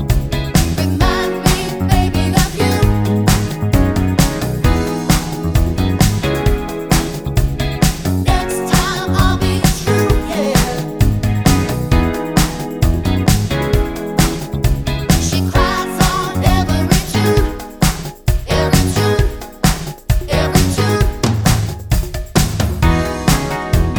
no unison Backing Vocals Soul / Motown 4:00 Buy £1.50